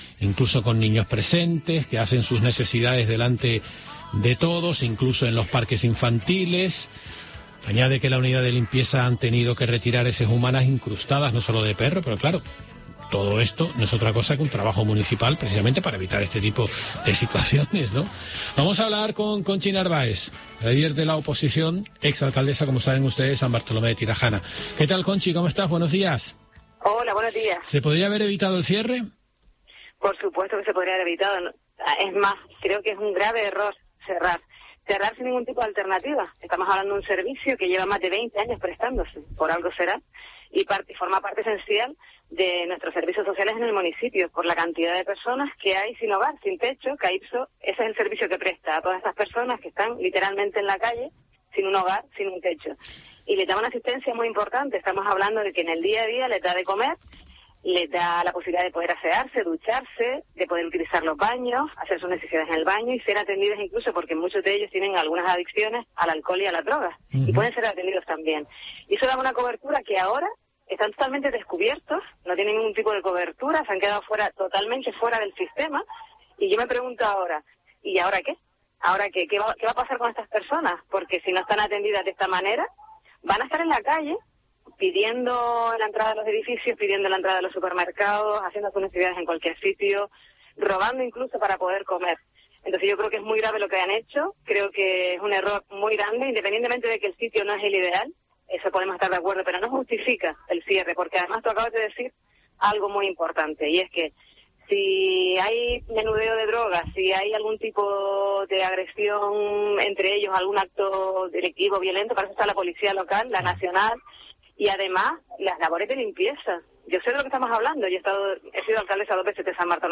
Conchi Narváez, portavoz del PSOE en el ayuntamiento de San Bartolomé de Tirajana
En Herrera en COPE Gran Canaria hemos hablado con Conchi Narváez, exalcaldesa y portavoz del PSOE en el ayuntamiento.